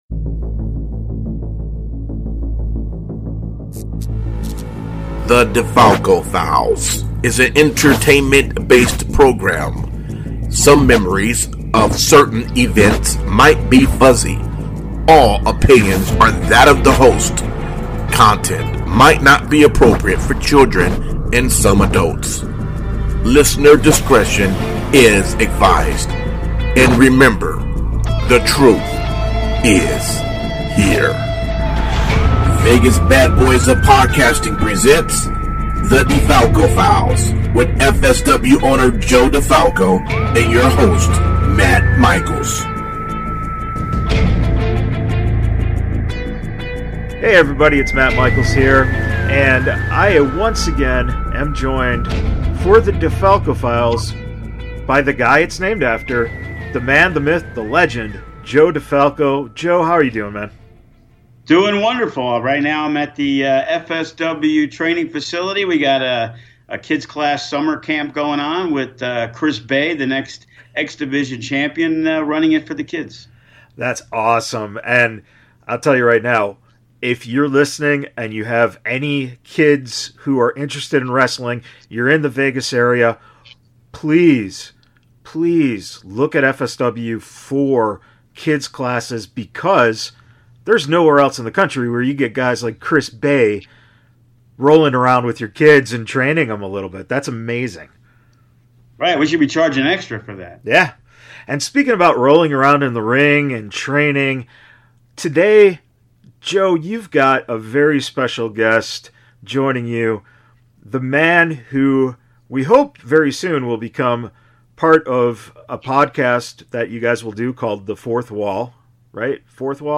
It’s a very informative conversation that you can only get here on the “DeFalco Files”.